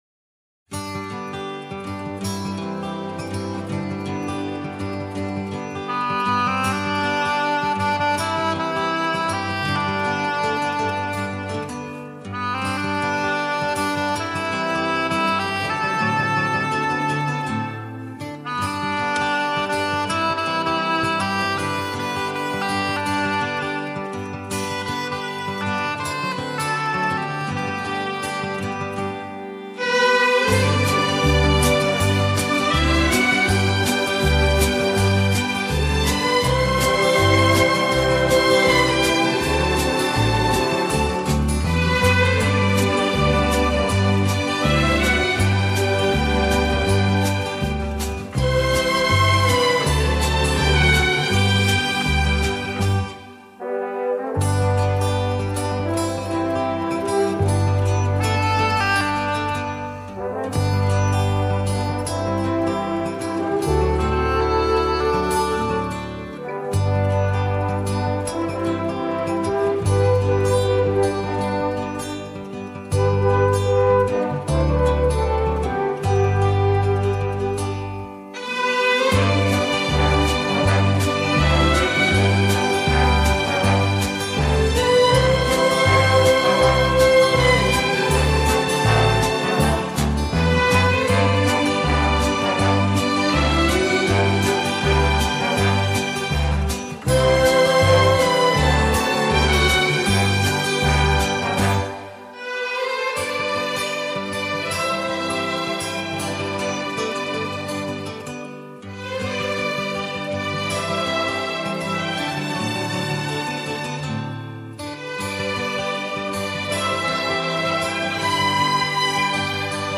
Genres:Easy Listening